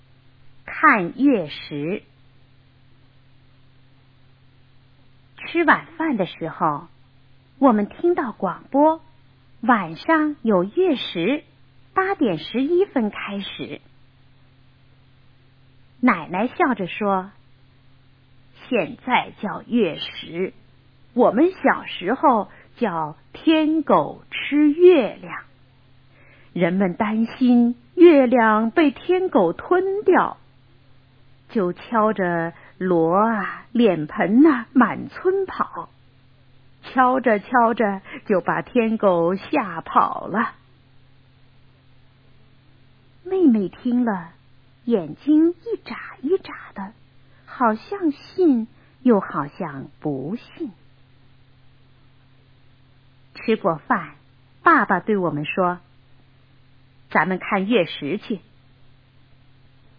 看月食 课文朗读